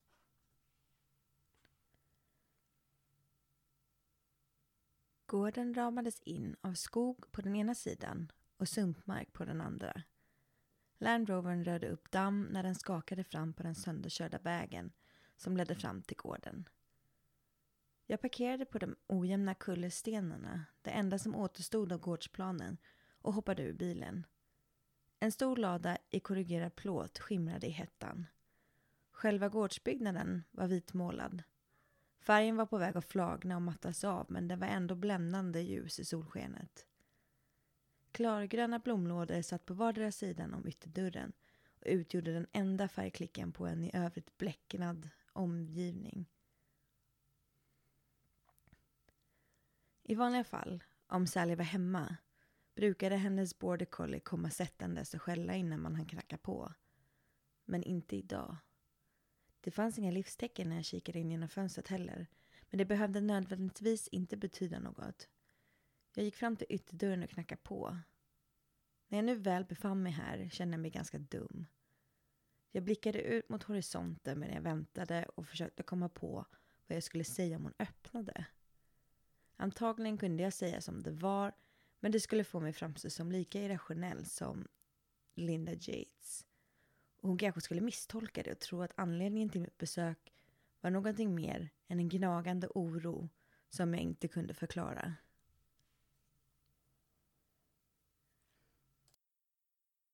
I seem to get a lot of clicks when recording and would really appreciate help/feedback.
(Shure SM58 > RolandRubix audio interface > desktop computer).
The file I attached is the original recording without any processing.
This Nyquist code will not help clicks, (or hiss), but will remove the faint constant whine …
BTW there is a loud 10Hz signal on your original …